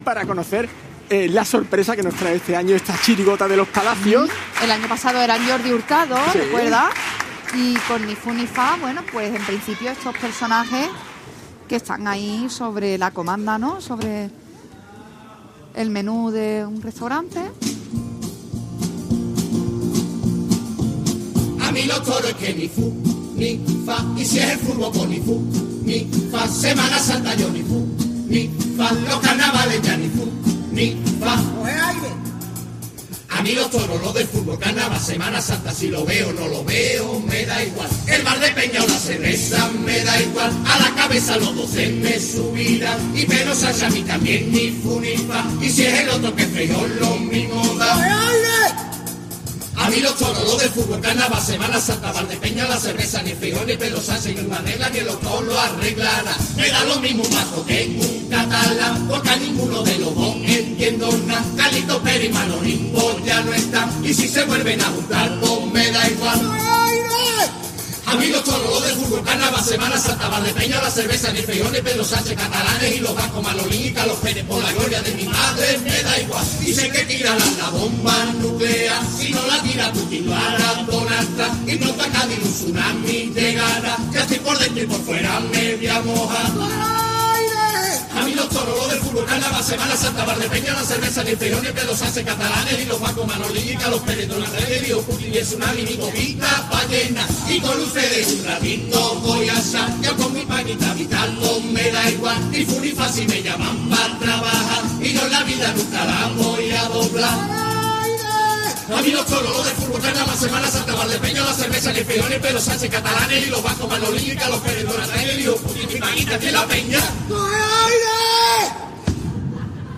en la fase preliminares del COAC Carnaval de Cádiz 2025